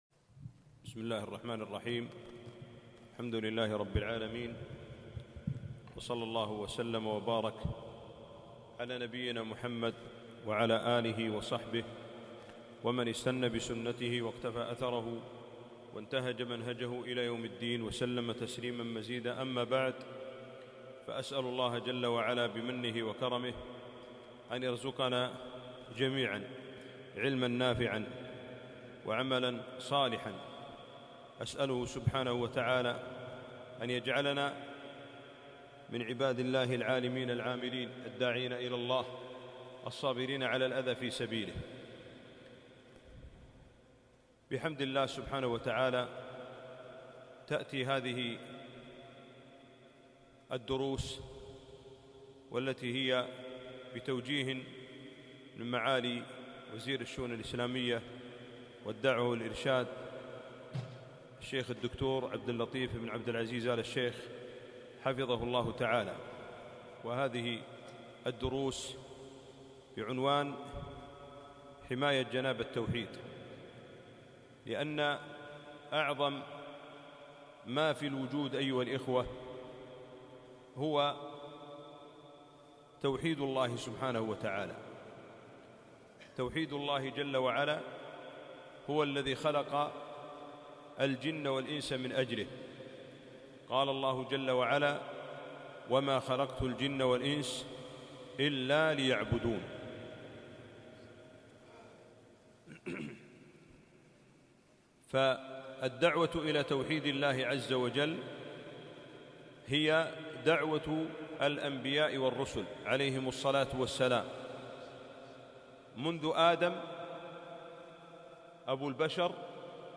كلمة - حماية جناب التوحيد